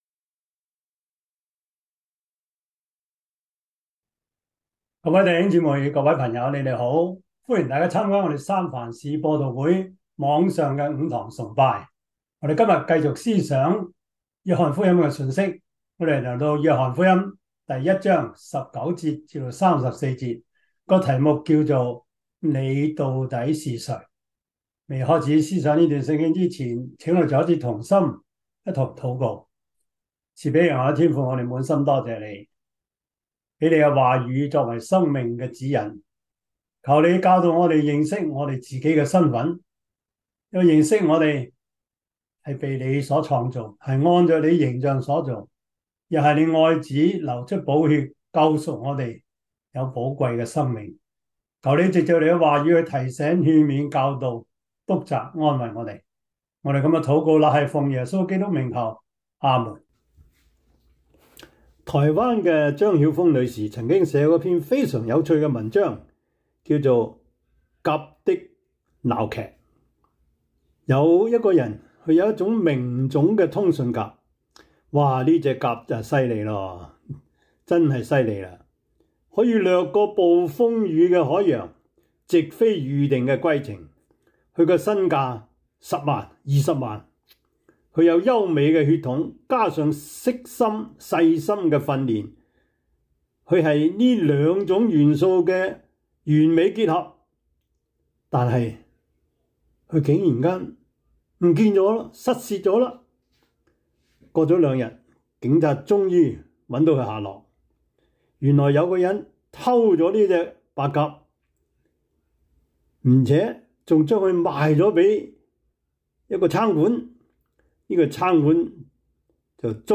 約翰福音 1:19-34 Service Type: 主日崇拜 約翰福音 1:19-34 Chinese Union Version
」 Topics: 主日證道 « 人已經老了，如何能重生呢?